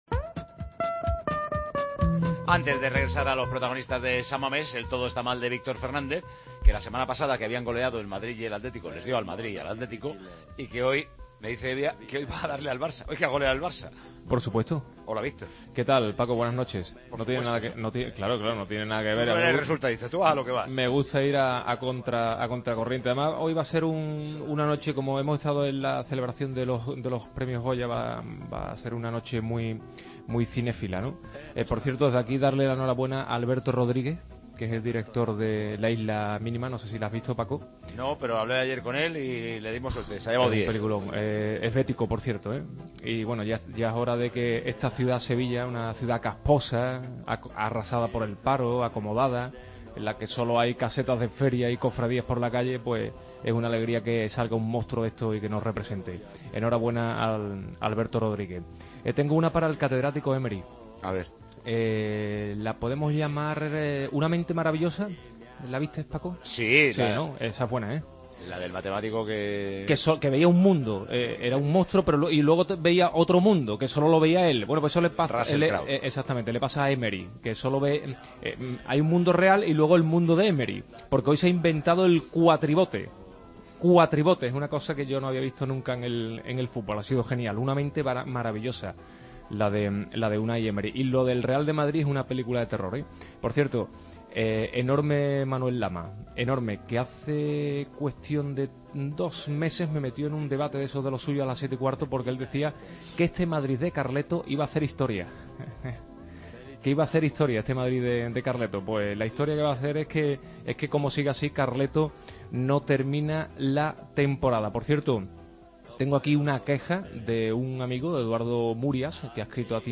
Nos puso sintonías de bandas sonoras de películas para sacar parecidos con el club azulgrana.